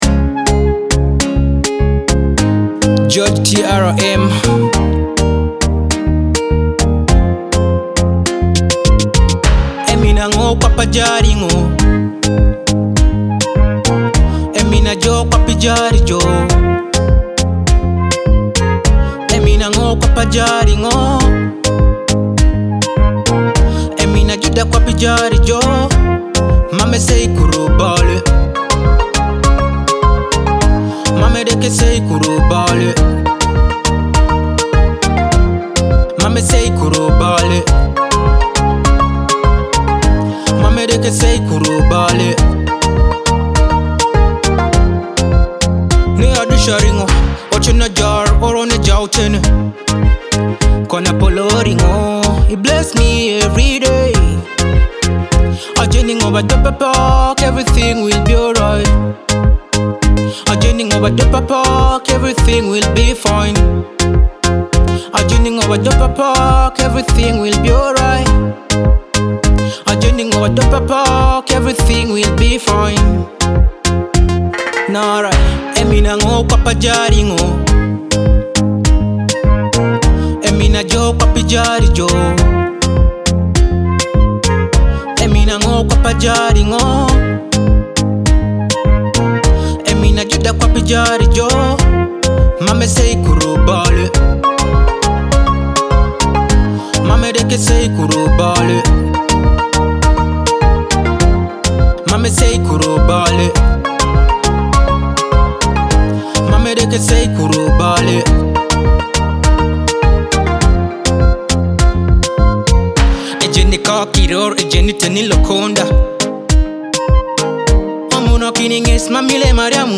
With uplifting melodies and faith-filled lyrics